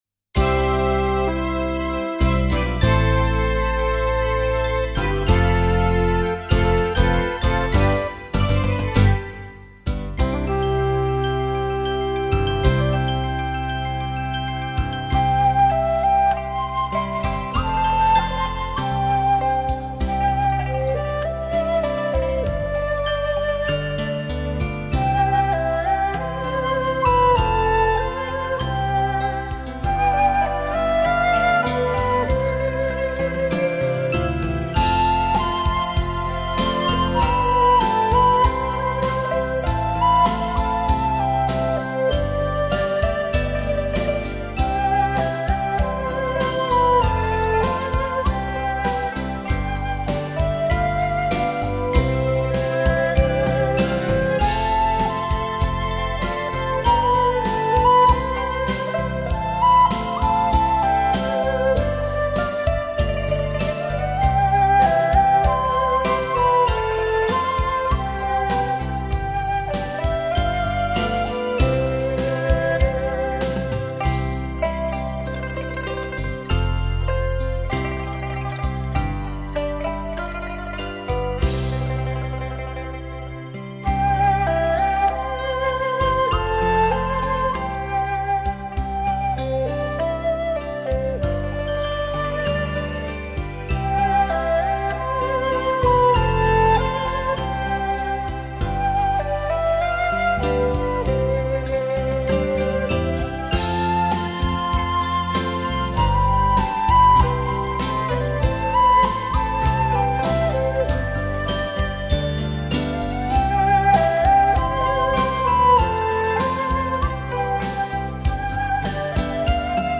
洞箫
古筝
琵琶
二胡